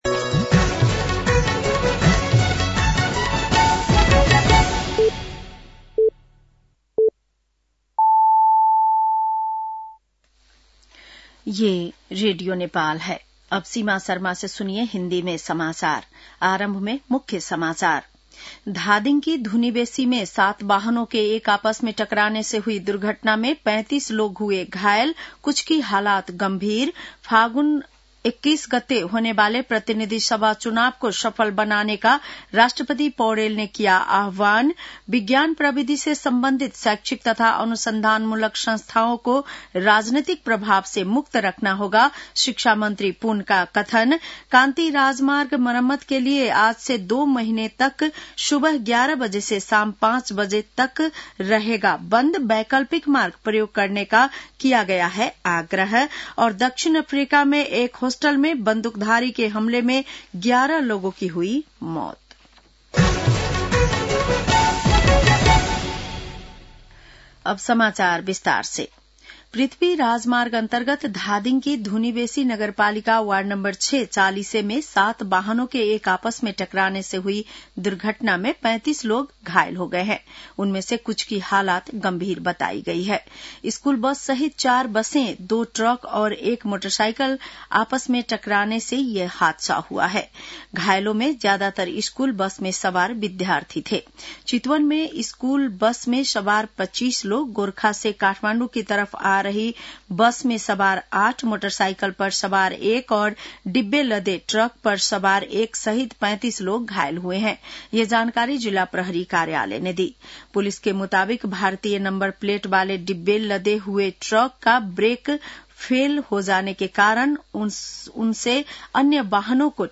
बेलुकी १० बजेको हिन्दी समाचार : २० मंसिर , २०८२